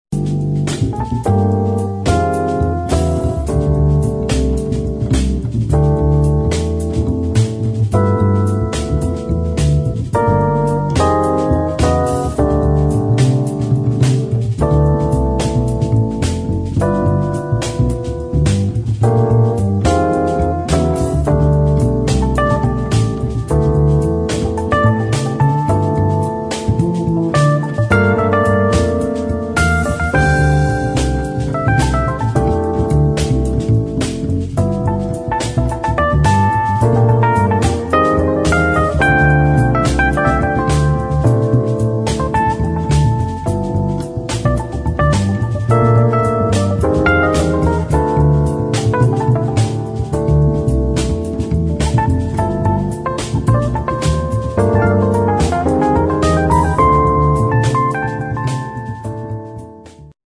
[ JAZZ / ELECTRONIC ]